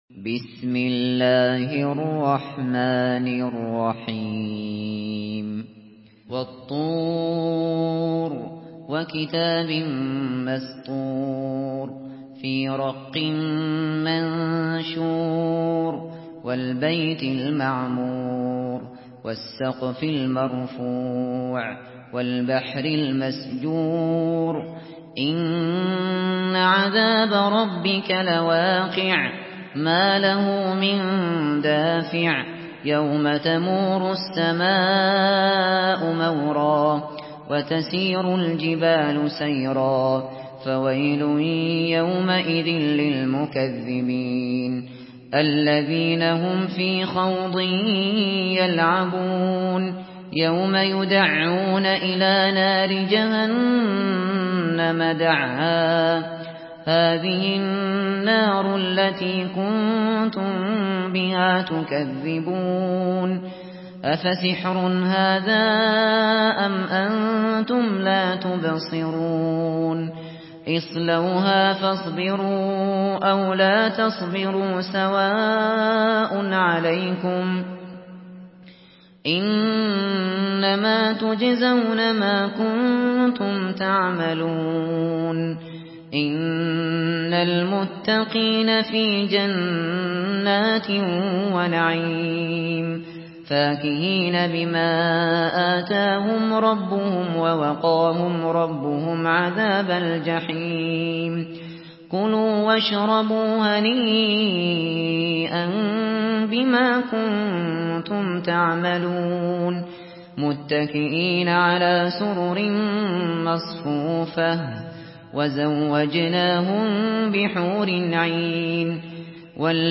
Surah الطور MP3 by أبو بكر الشاطري in حفص عن عاصم narration.
مرتل حفص عن عاصم